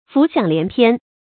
注音：ㄈㄨˊ ㄒㄧㄤˇ ㄌㄧㄢˊ ㄆㄧㄢ
浮想聯翩的讀法